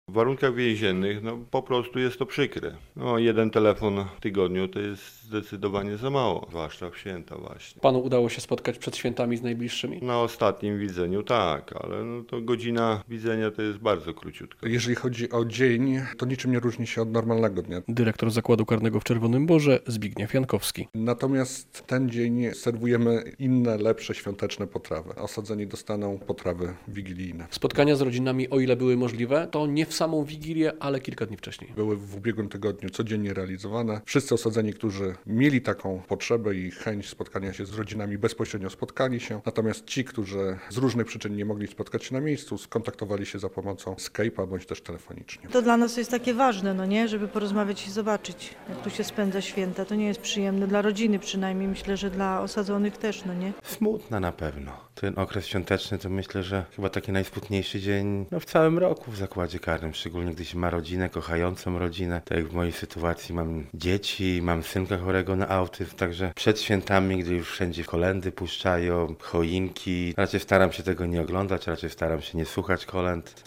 - Jedna rozmowa w tygodniu, czy krótkie spotkanie to za mało, zwłaszcza w święta. Staramy się przygotować coś wyjątkowego na ten dzień, ale to niewiele zmienia - mówił inny osadzony w Zakładzie Karnym w Czerwonym Borze.